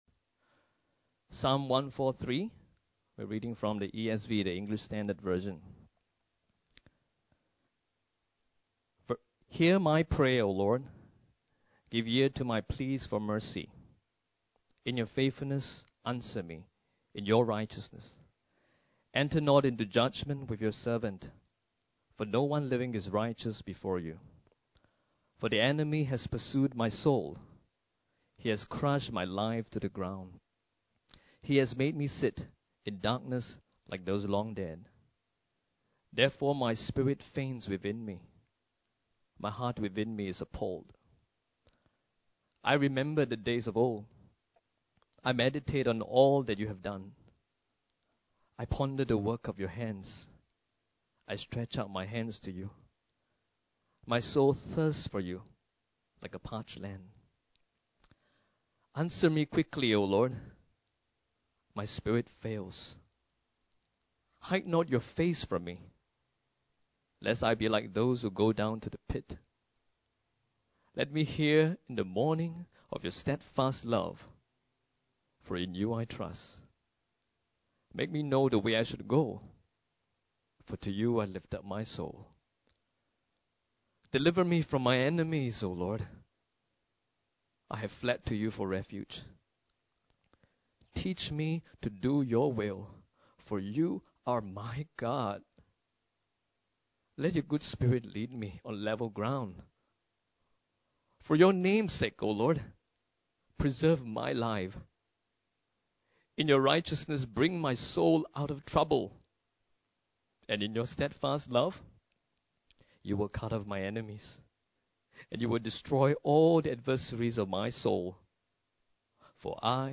Sermon Slides The Cry for Mercy_Slides